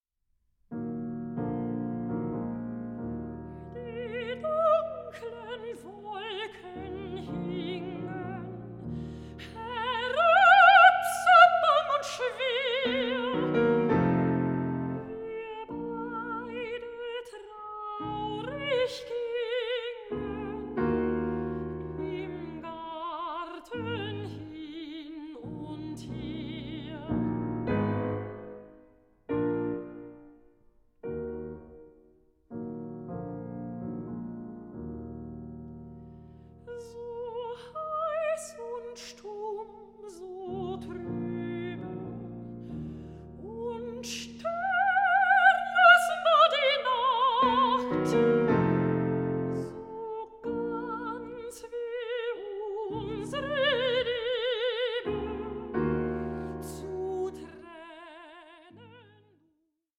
soprano
pianist